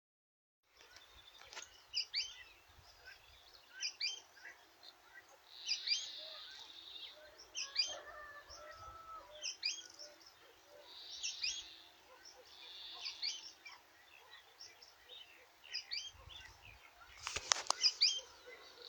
Pijuí Frente Gris (Synallaxis frontalis)
Nombre en inglés: Sooty-fronted Spinetail
Localidad o área protegida: Reserva Natural Urbana La Malvina
Condición: Silvestre
Certeza: Vocalización Grabada